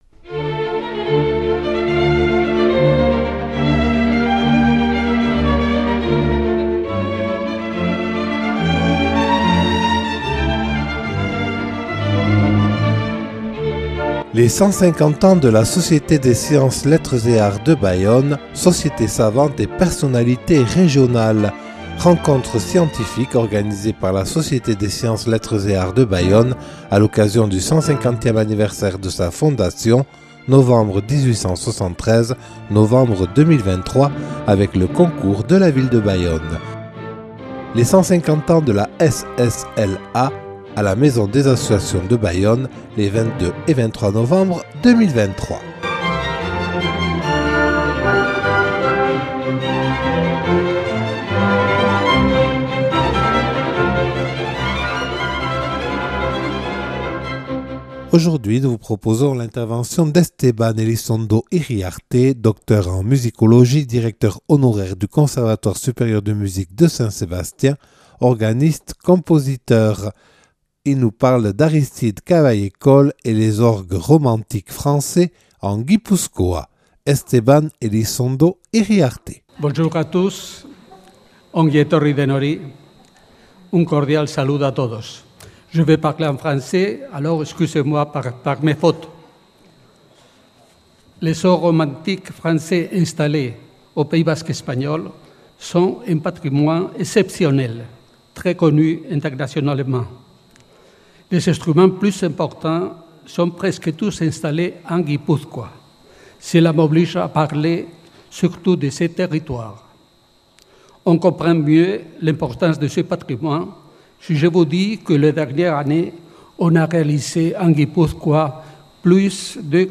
Les 150 ans de la Société des Sciences, Lettres et Arts de Bayonne – (8) – Rencontre scientifique des 22 et 23 novembre 2023